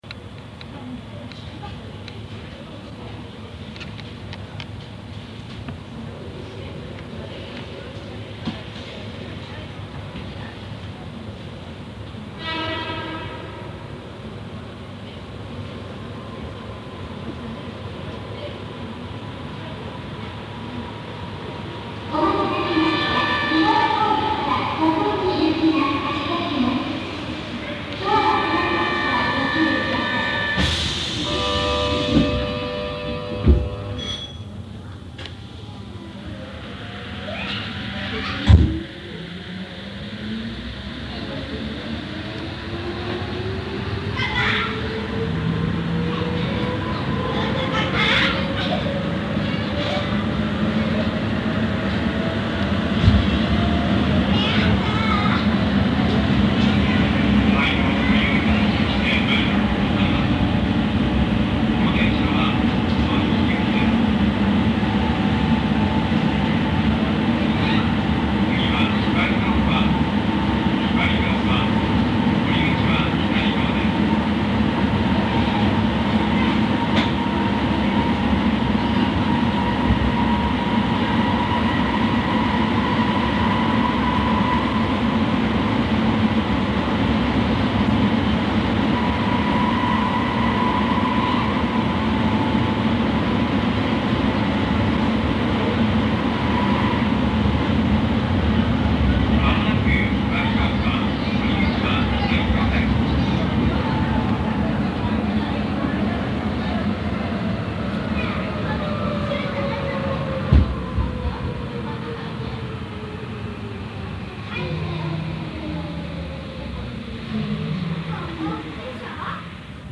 SCSIS : 6000形の走行音
最近になってICレコーダーで収録しなおした、6000形603号車の走行音です。 宮の沢→発寒南間では、今回は曲線部は通過していません(時間調整のために低速運転を行っています)。